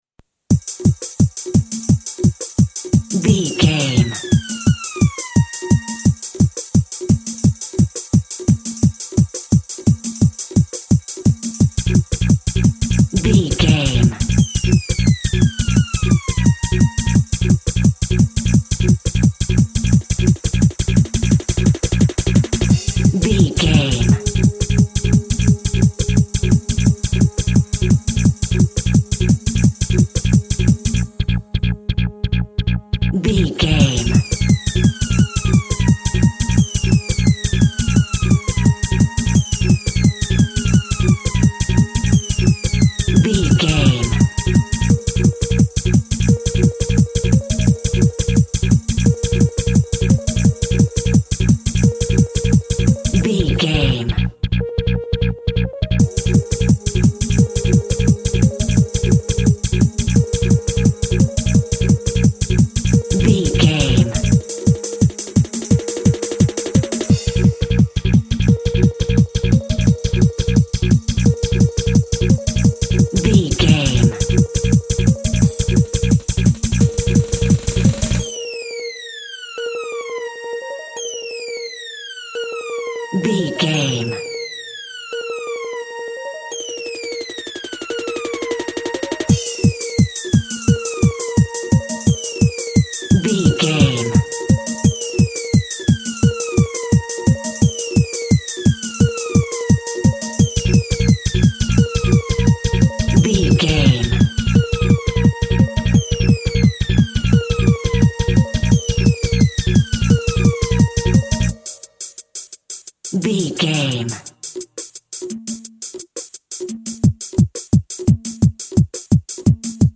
Aeolian/Minor
B♭
Fast
driving
energetic
futuristic
hypnotic
frantic
drum machine
electronic
techno
synth lead
synth bass
Electronic drums
Synth pads